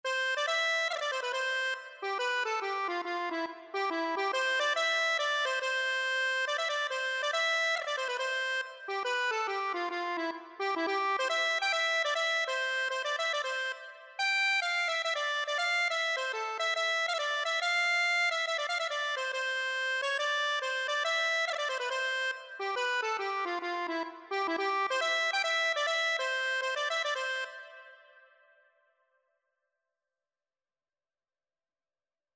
C major (Sounding Pitch) (View more C major Music for Accordion )
4/4 (View more 4/4 Music)
E5-G6
Accordion  (View more Easy Accordion Music)
Traditional (View more Traditional Accordion Music)